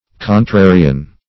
contrarian \con*tra"ri*an\, n. [see contrarian, a..]